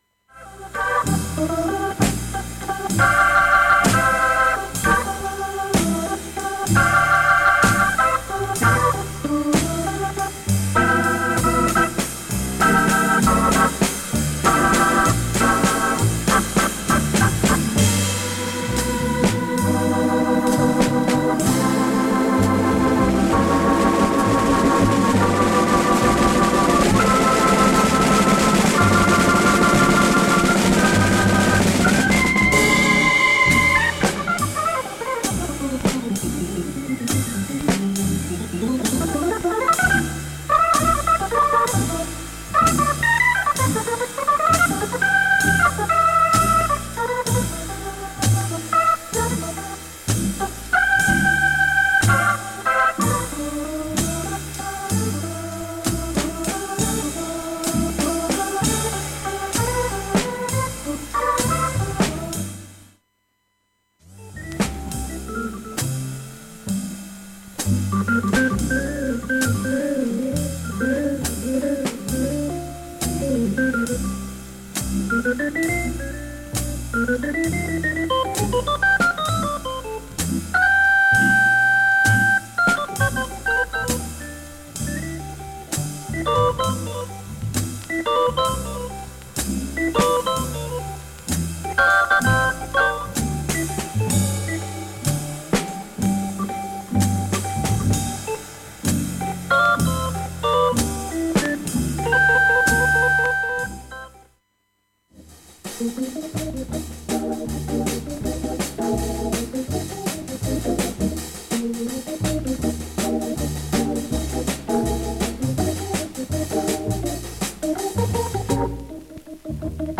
現物の試聴（バブル箇所いくつかとB-1初めサーフェス）できます。
ほかスレなど影響なし音質良好全曲試聴済み。